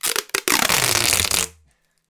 tape_roll1.wav